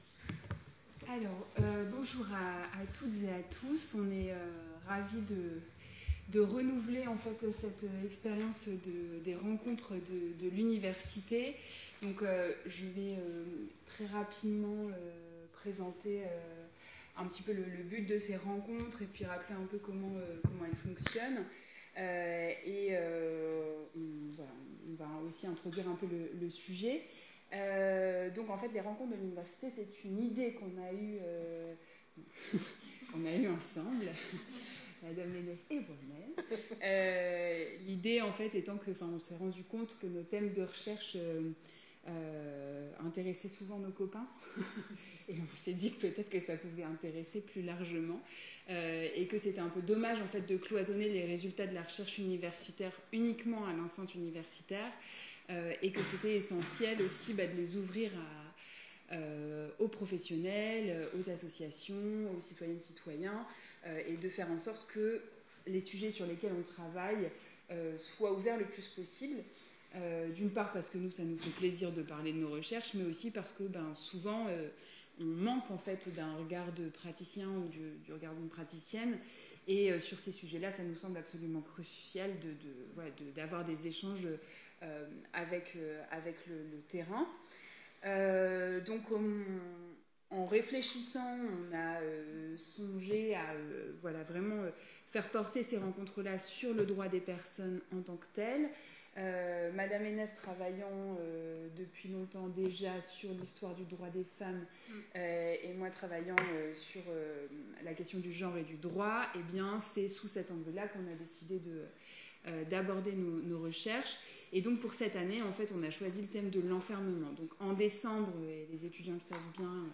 Écouter la rediffusion de la conférence - 47 Mo, MP3